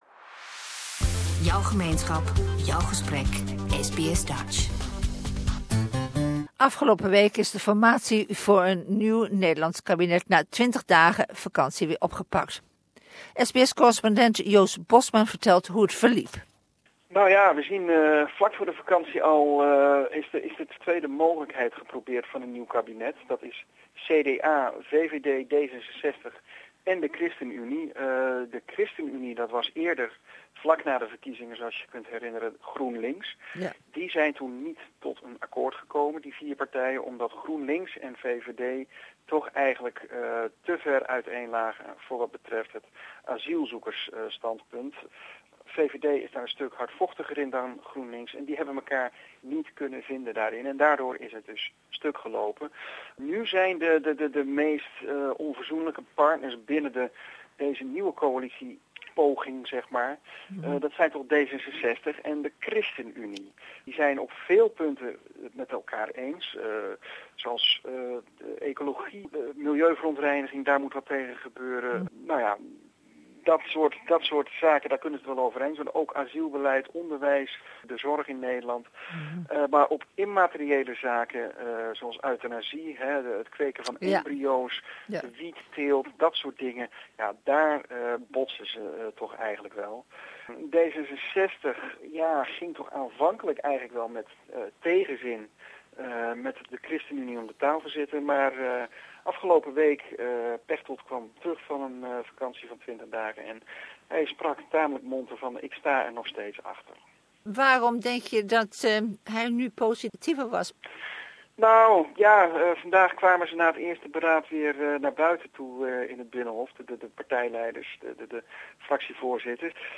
For almost 6 months attempts have been made to form a new government but with little success. Will these latest talks lead to a new Dutch government? A report